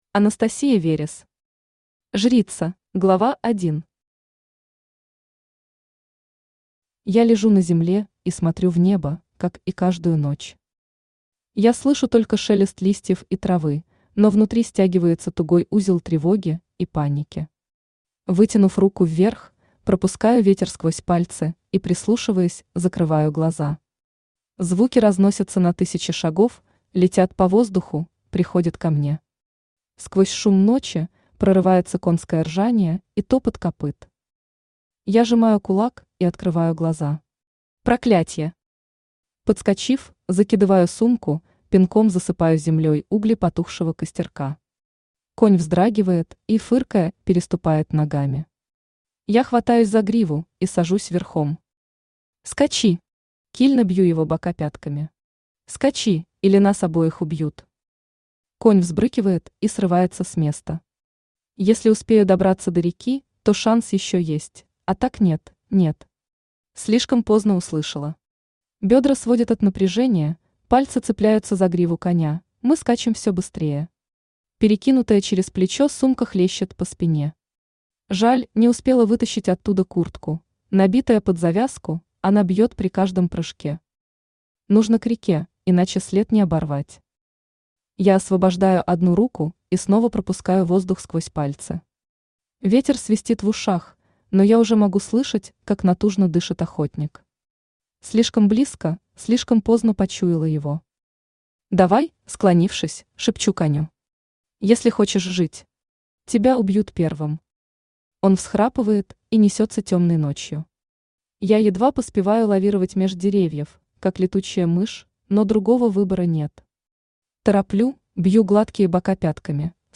Аудиокнига Жрица | Библиотека аудиокниг
Aудиокнига Жрица Автор Анастасия Верес Читает аудиокнигу Авточтец ЛитРес.